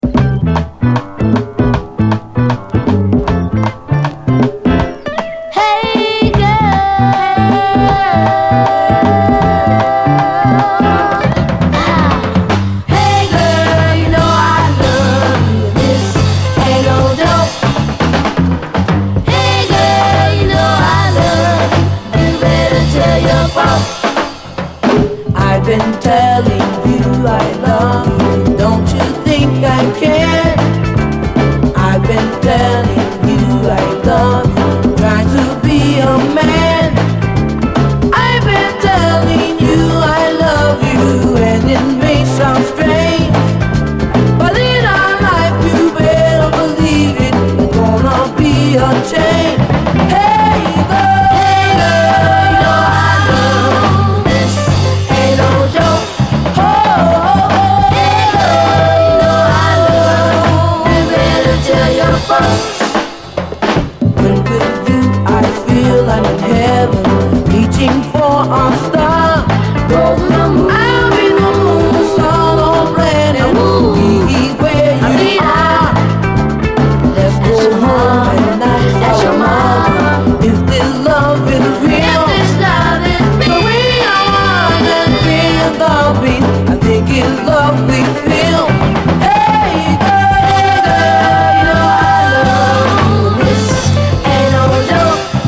SOUL, 70's～ SOUL, 7INCH
人気レア・アルバムからのカットで、キッズ・ソウルの王道を行く、キュートかつグルーヴィーな一曲！